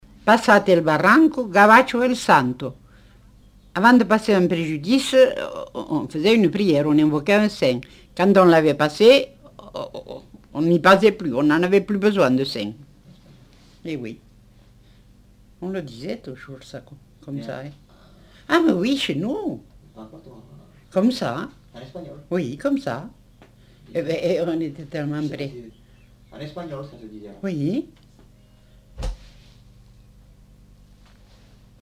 Lieu : Montauban-de-Luchon
Genre : forme brève
Effectif : 1
Type de voix : voix de femme
Production du son : récité
Classification : locution populaire